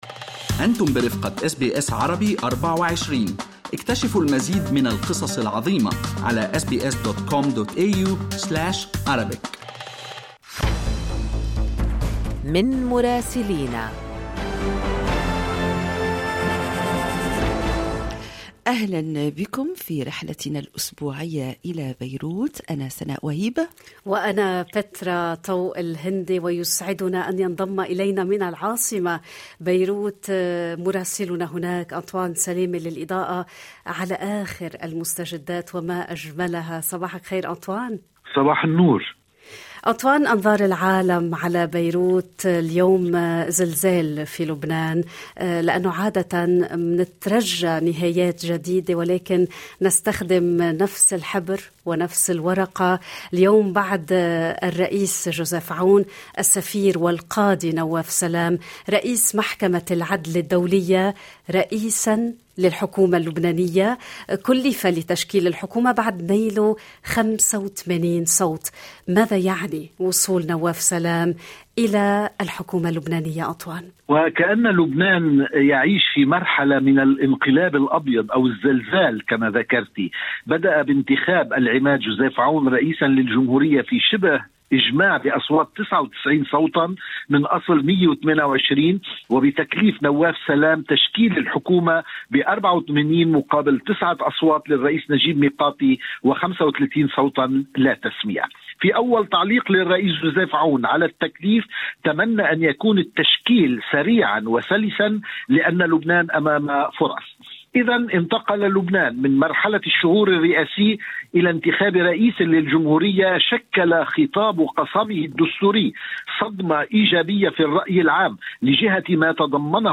يمكنكم الاستماع إلى تقرير مراسلنا في العاصمة بيروت بالضغط على التسجيل الصوتي أعلاه.